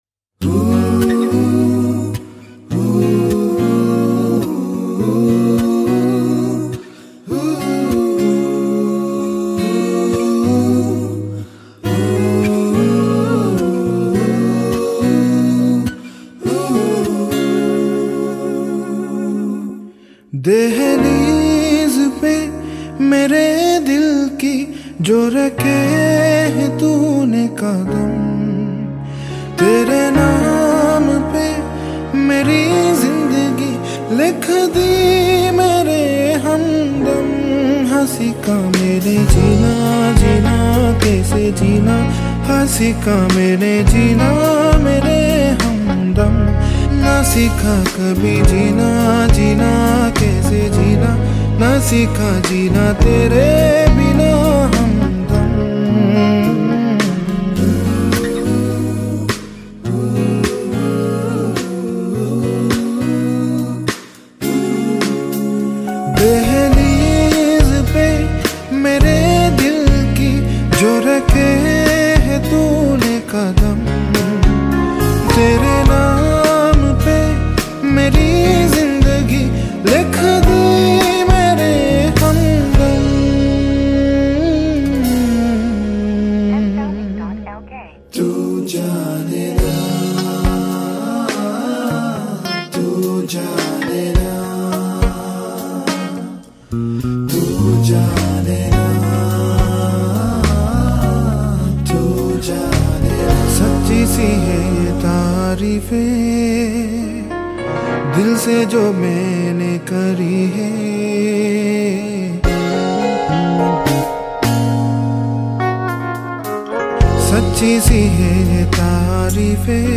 High quality Sri Lankan remix MP3 (5).
remix